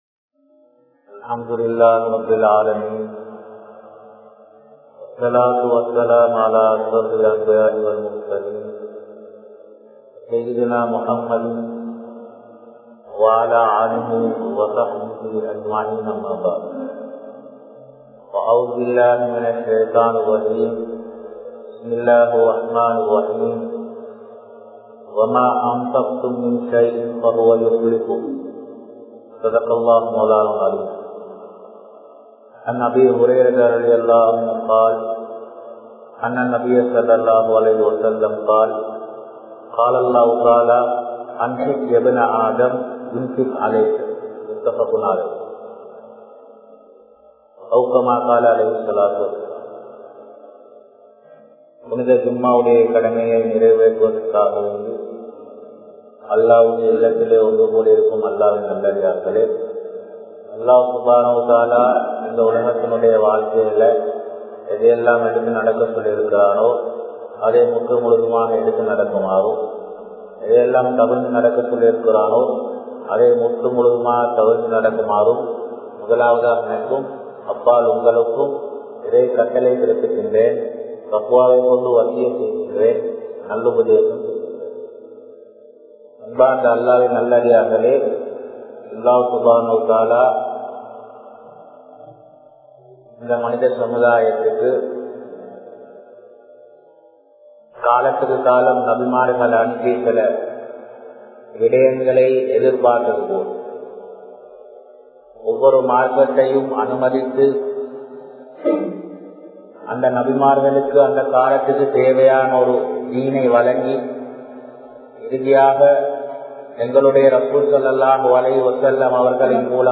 Sathaqaavin Mukkiyathuvam (ஸதகாவின் முக்கியத்துவம்) | Audio Bayans | All Ceylon Muslim Youth Community | Addalaichenai
Hameediyya Jumua Masjidh